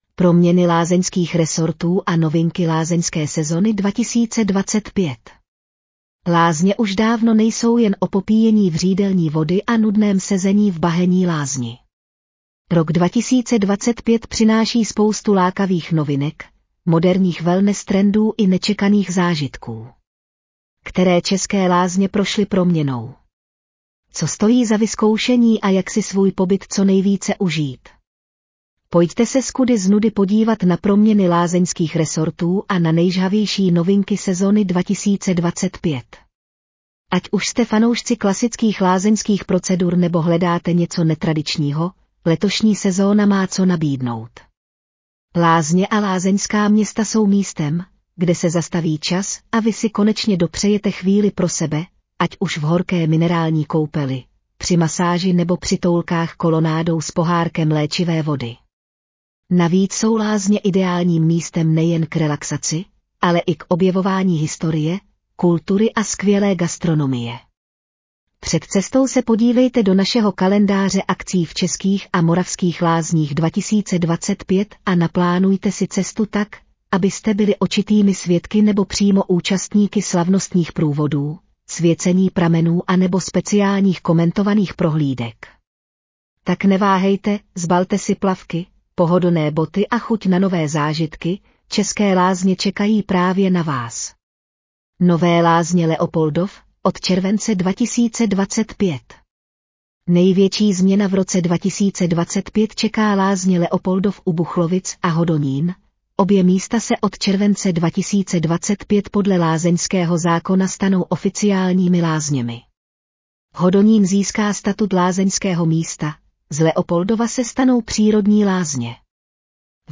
Audio verze článku Proměny lázeňských resortů a novinky lázeňské sezony 2024